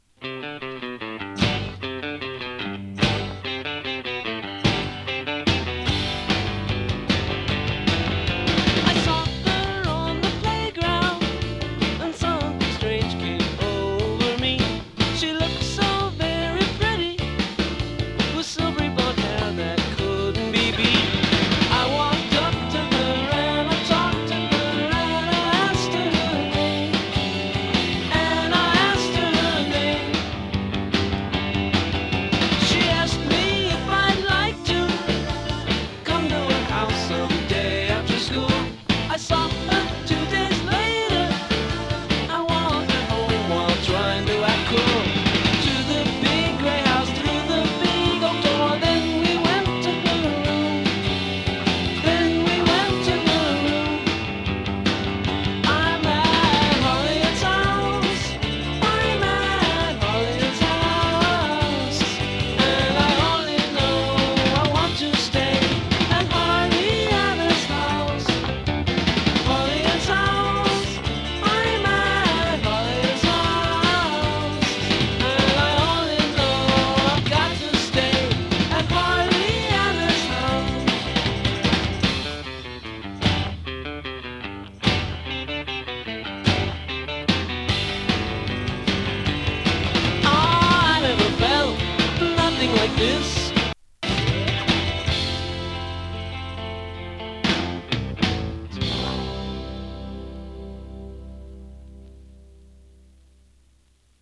ラヴリーパワーポップ
インディーポップ